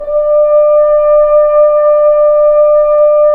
Index of /90_sSampleCDs/Roland L-CDX-03 Disk 2/BRS_French Horn/BRS_F.Horn 3 pp